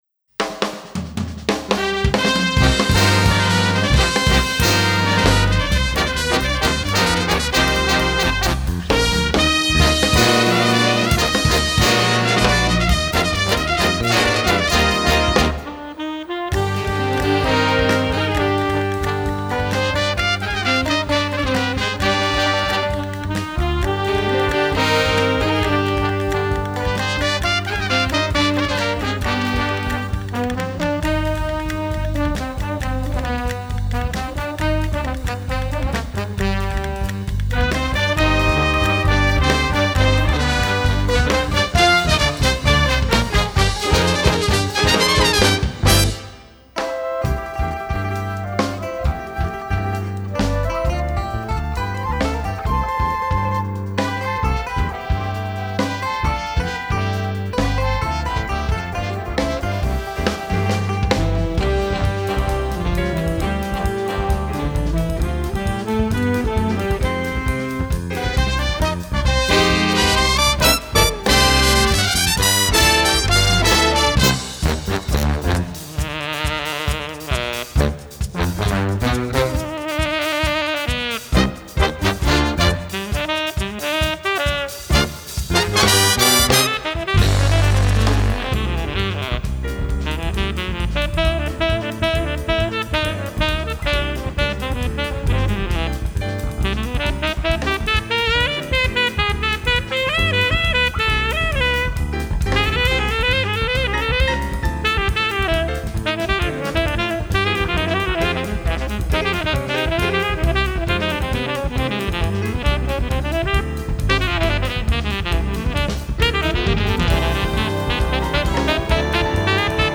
tenor sax
piano.